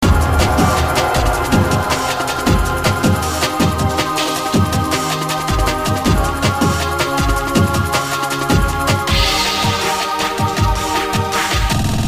dark drum 'n bass track